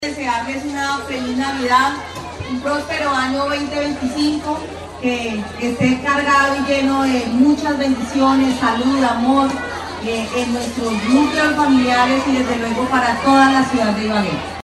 En un ambiente lleno de música, alegría y espíritu navideño, la alcaldesa Johana Aranda llevó a cabo la cuarta novena de Navidad en Arboleda del Campestre, comuna 9, en compañía del IBAL e Infibagué.